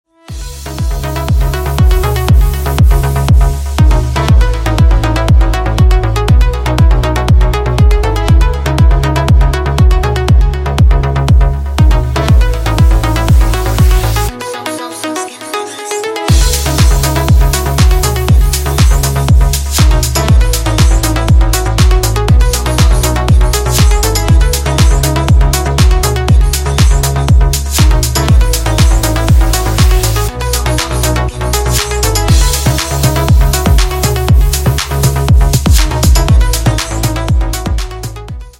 • Качество: 320 kbps, Stereo
Ремикс
без слов